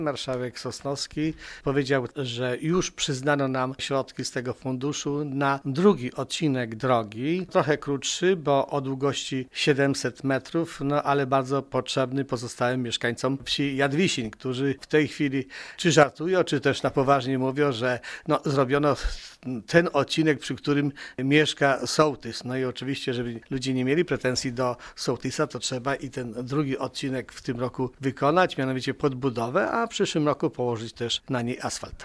Zastępca wójta Wiktor Osik wyjaśnia, że podobnie jak zakończona inwestycja, również ta planowana będzie współfinansowana ze środków Funduszu Ochrony Gruntów Rolnych: